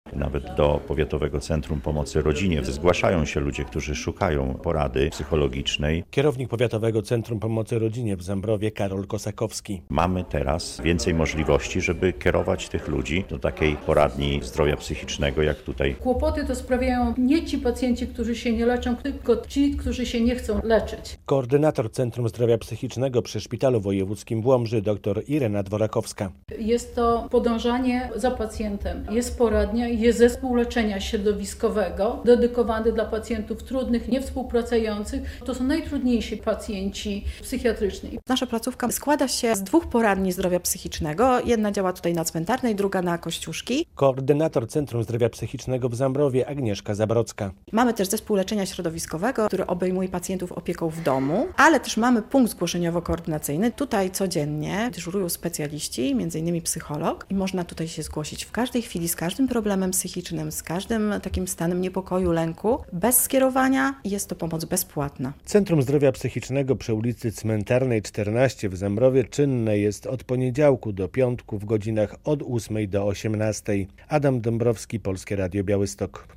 W Zambrowie oddano do użytku Centrum Zdrowia Psychicznego - relacja